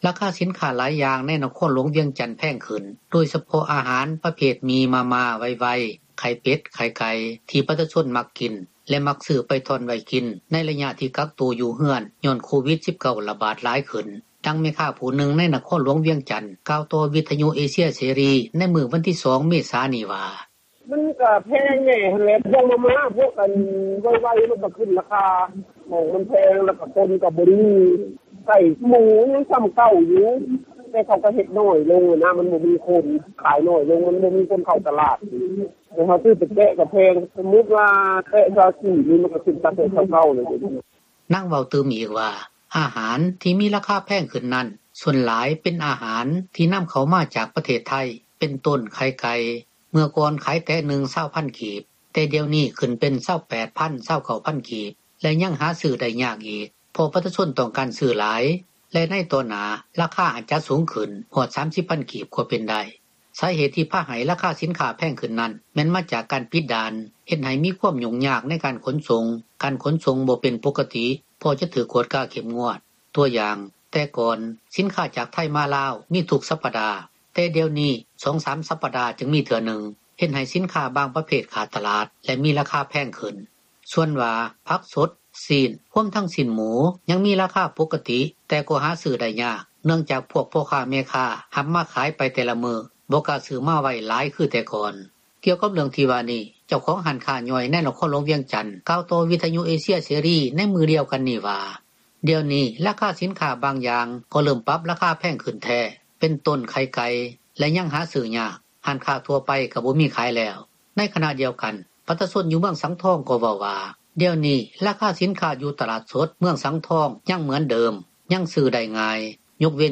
ກ່ຽວກັບເຣຶ່ອງທີ່ວ່ານີ້ ເຈົ້າຂອງຮ້ານຄ້າຍ່ອຍ ໃນນະຄອນຫລວງວຽງຈັນ ກ່າວຕໍ່ວິທຍຸເອເຊັຍເສຣີ ໃນມື້ດຽວກັນນີ້ວ່າ: